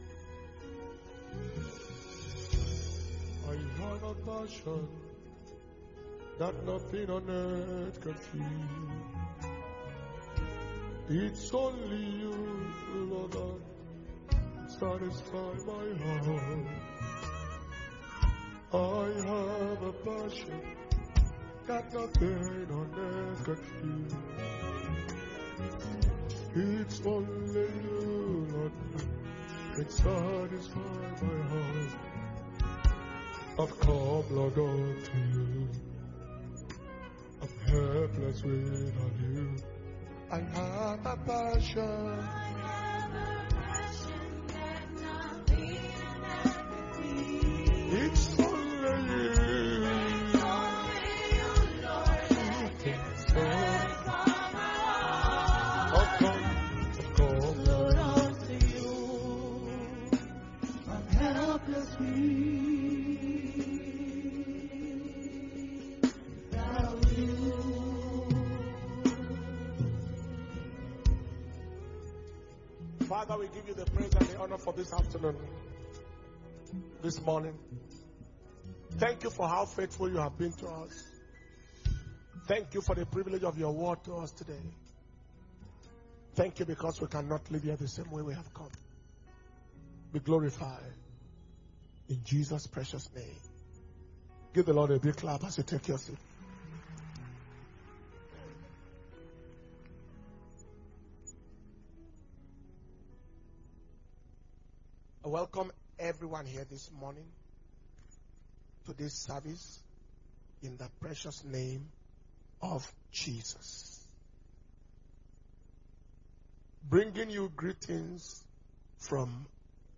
October 2021 Holy Ghost Service